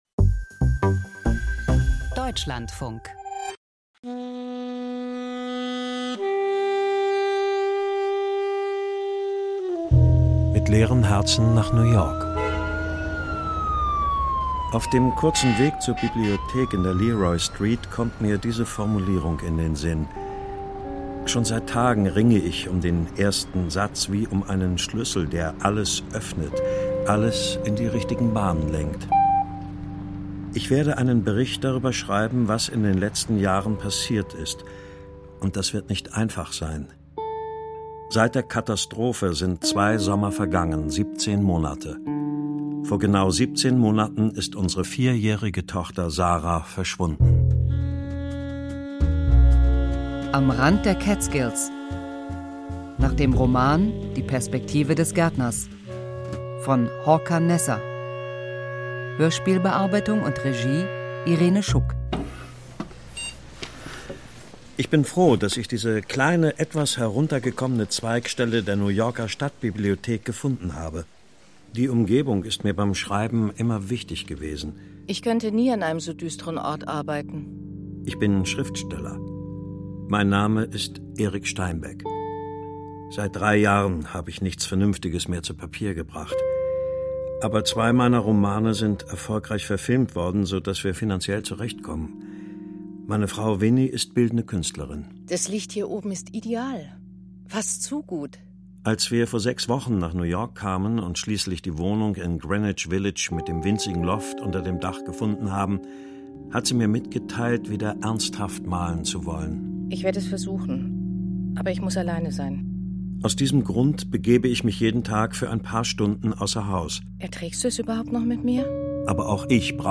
Klangkunst mit künstlicher Intelligenz - Seeking Truth Seeking